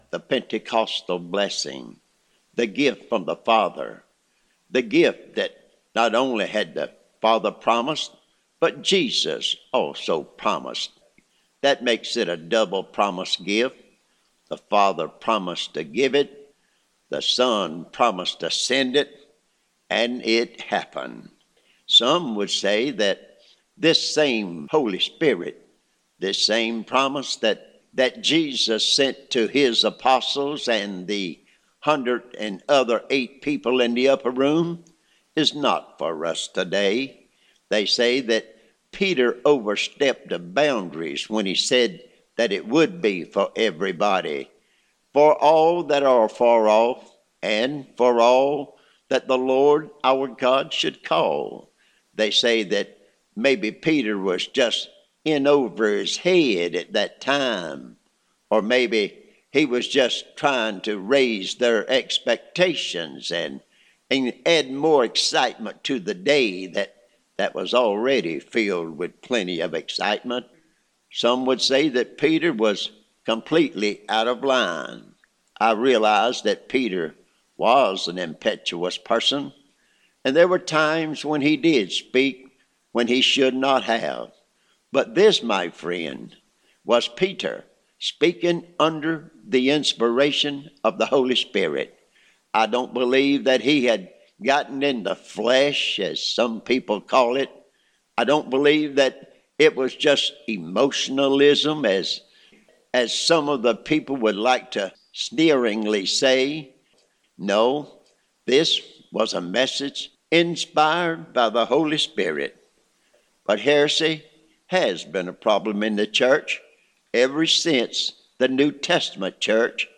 Recent Sermons